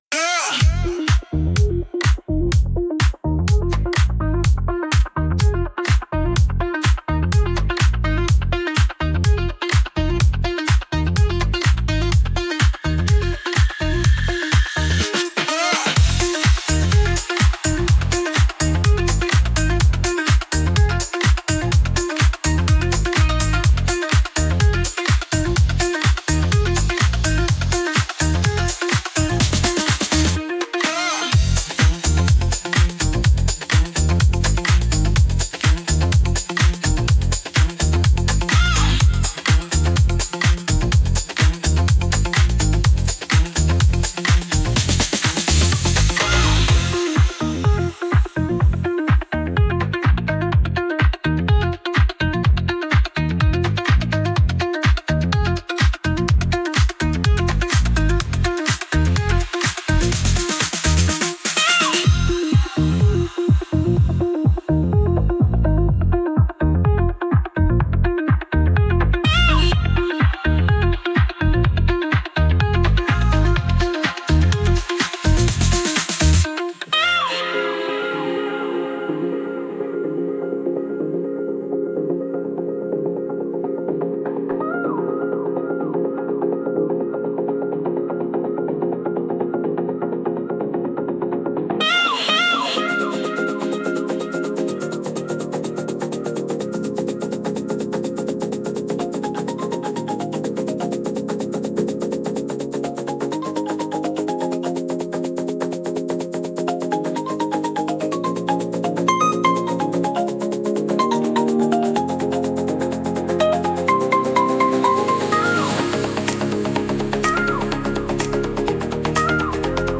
フロアを巨大なダンスパーティー会場に変える、ノリノリのEDM！
ディスコで男女が踊り明かすような、華やかで都会的な雰囲気が特徴です。
この曲の最大の武器は、ハウス特有の一定のリズムが続くこと。
変則的なビートがないため、演技中のカウントが非常に取りやすく、手具操作のタイミングも合わせやすいのが魅力です。